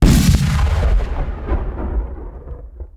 beamcannon.wav